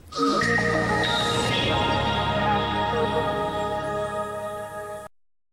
The weather sting.